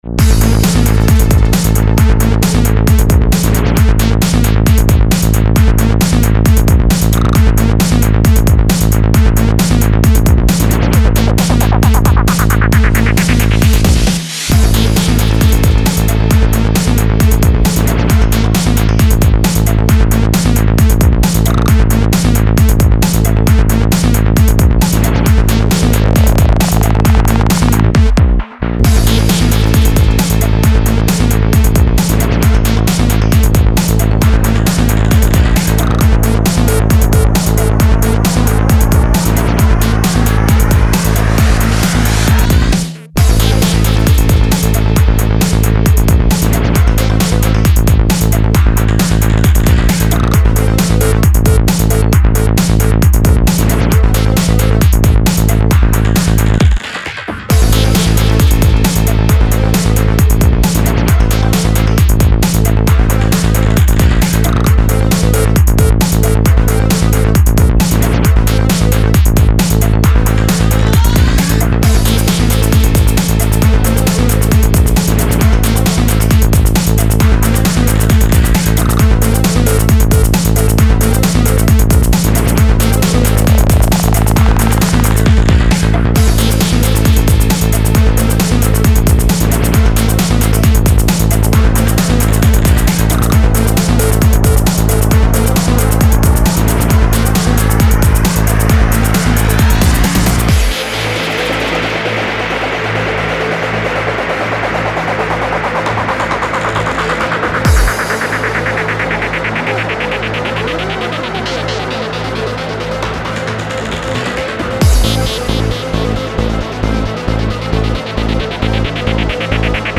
Genres Psy-Breaks